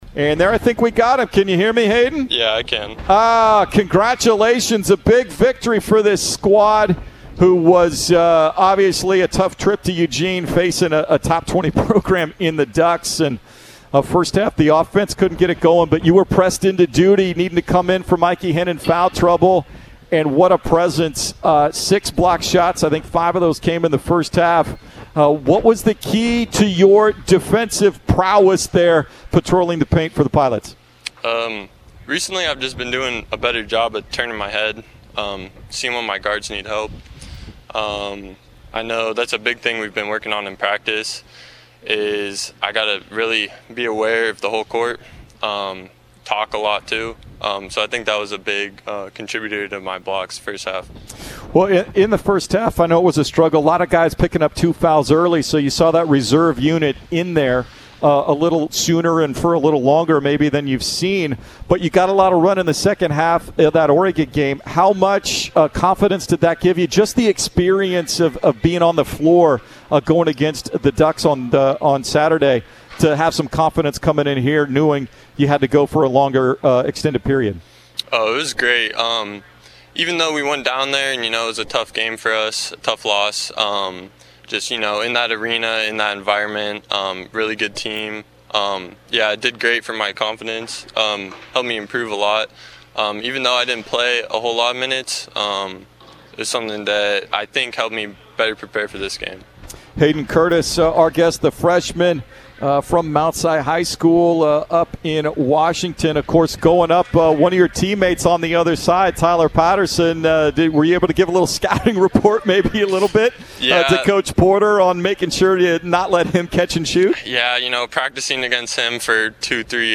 Courtesy of 910 ESPN-Portland (KMTT)
Men's Basketball Radio Interviews